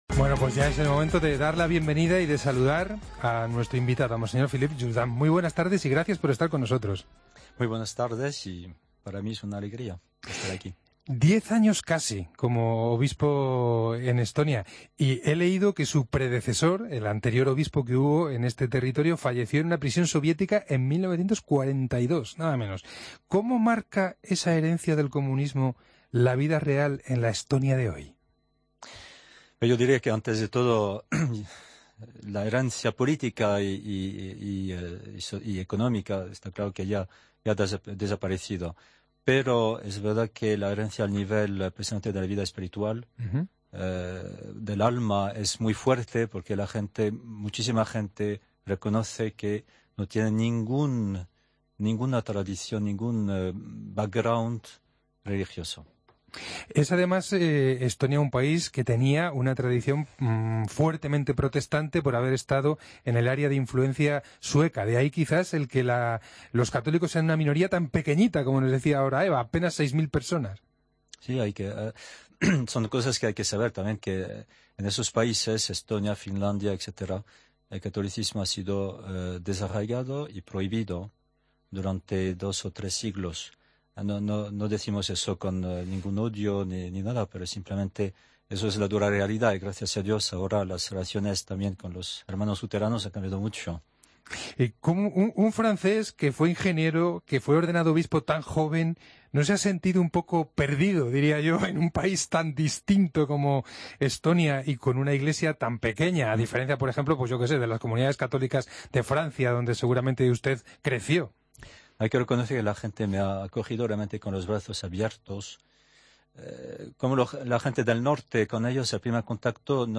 Mons. Jourdan, entrevista en El Espejo